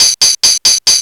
TAMB LOOP1-R.wav